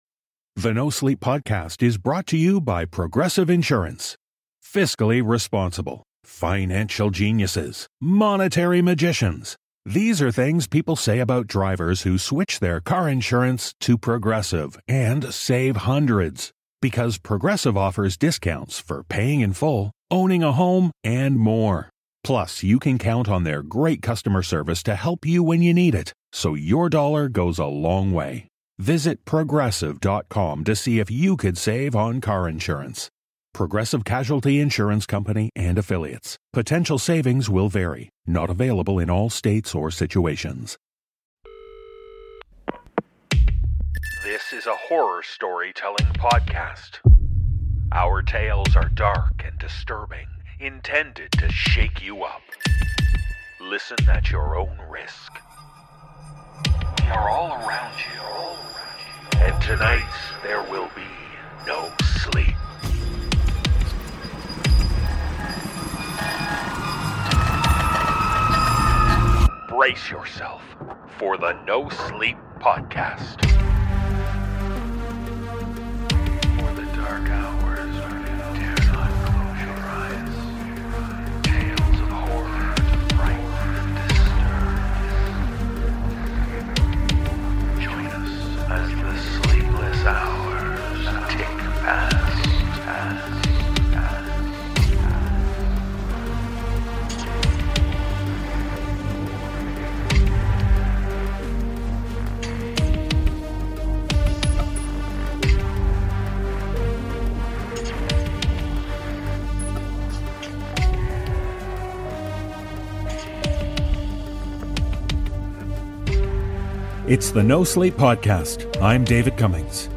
Click here to learn more about the voice actors on The NoSleep Podcast